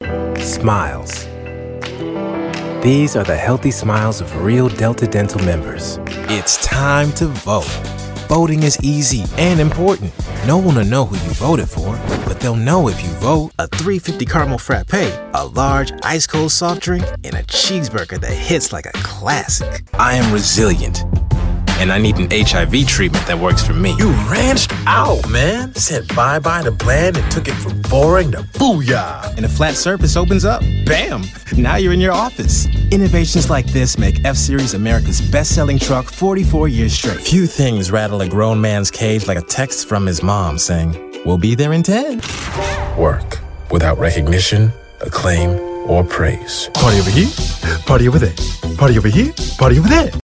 Chicago Voiceover